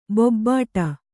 ♪ bobbāṭa